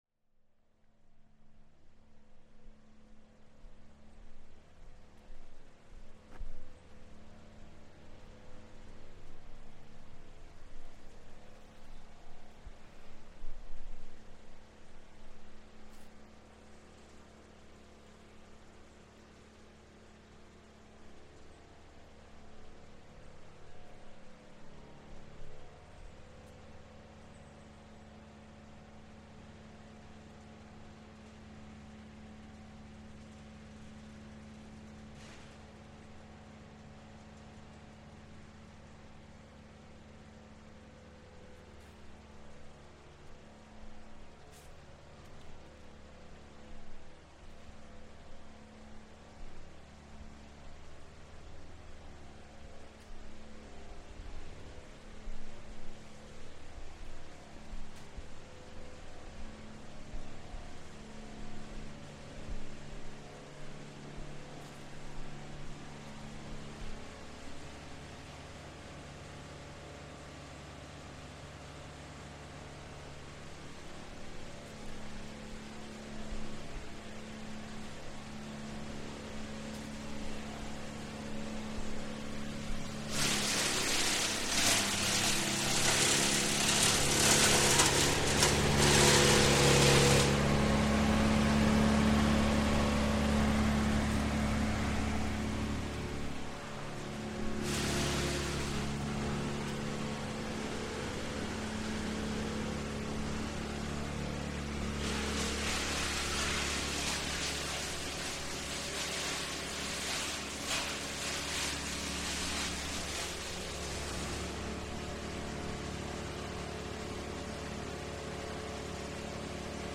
Industrial log disposal
The extremely loud business of tree and log disposal in Greenwich Park. Trees are trimmed, and the branches and logs are thrown into the back of the wood chipping truck, creating a violently loud industrial sound as they are turned into sawdust in an instant.